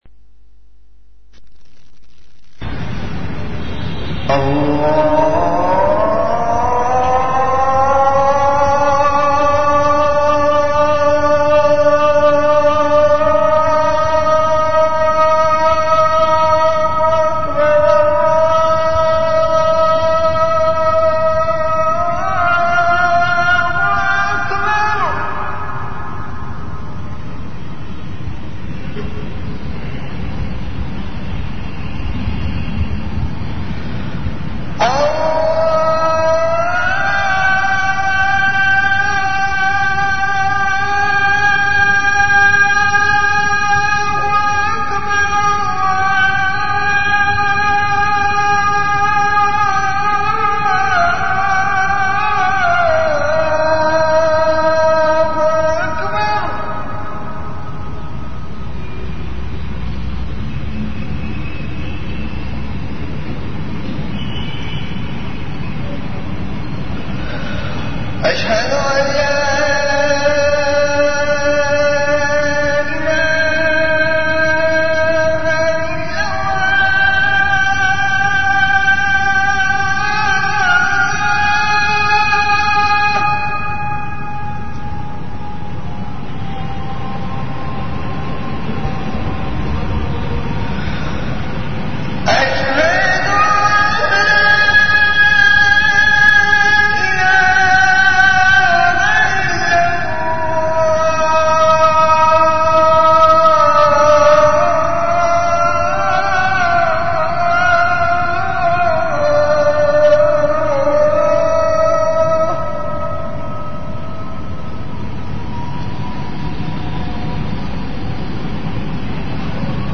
Azan · Jamia Masjid Bait-ul-Mukkaram, Karachi
CategoryAzan
VenueJamia Masjid Bait-ul-Mukkaram, Karachi
Event / TimeAfter Isha Prayer